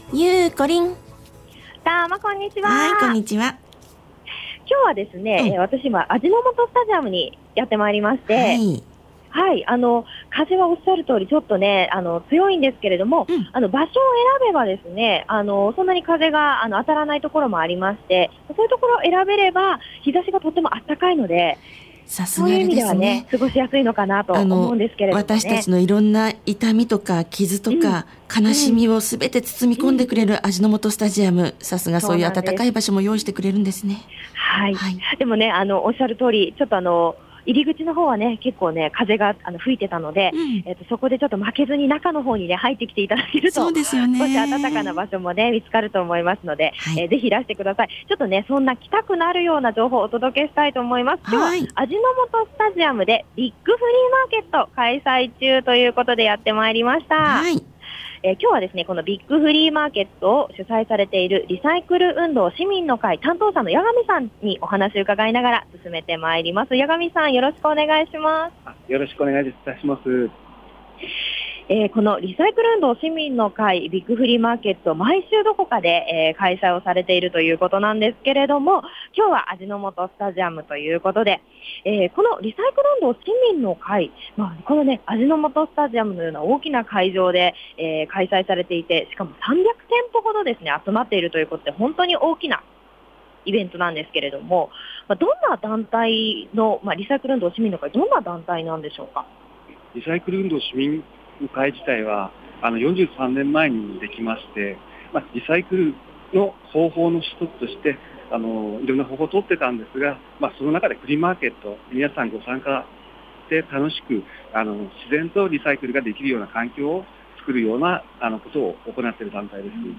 ★たまにち 街角レポート
午後4時まで開催されている味の素スタジアム BIGフリーマーケットにやってまいりました！